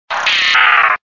Cri de Drascore dans Pokémon Diamant et Perle.